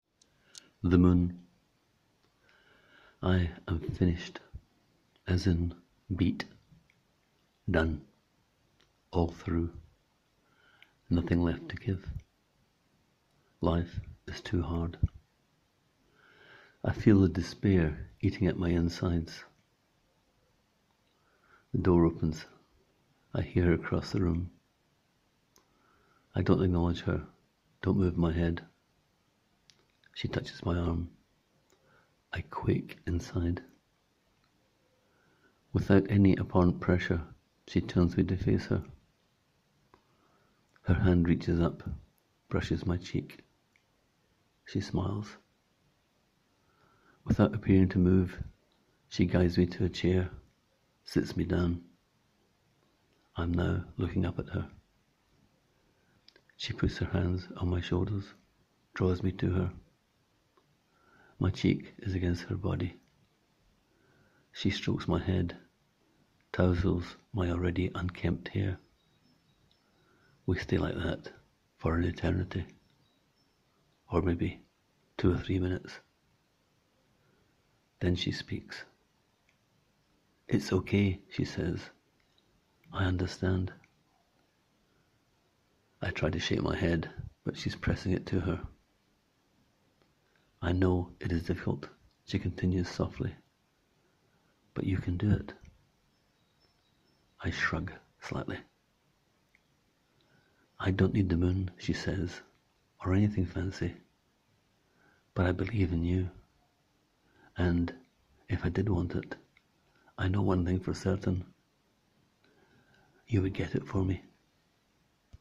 Click here to hear the author read the tale: